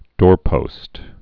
(dôrpōst)